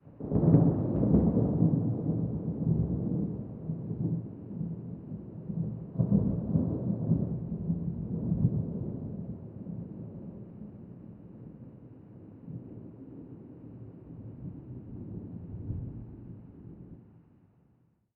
Rain and Thunder / 1Shot Weather Thunderclap ST450 01_ambiX.wav